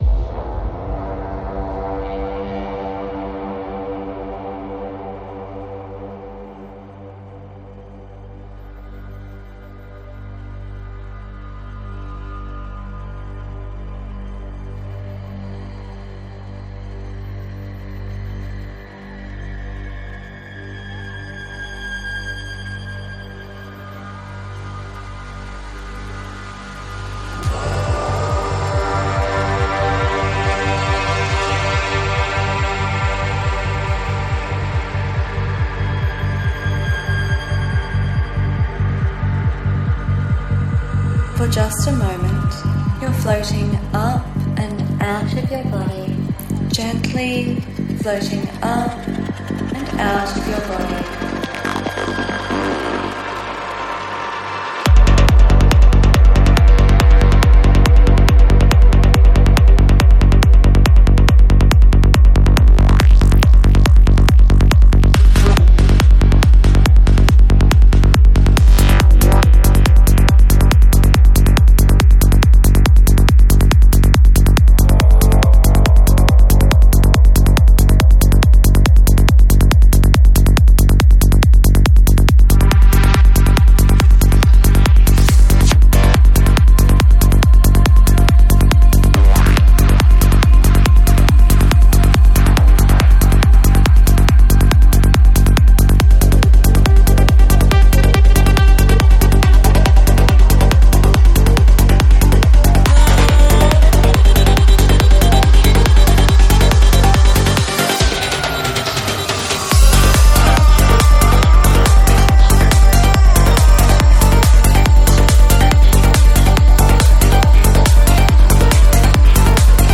Жанр: Psy-Trance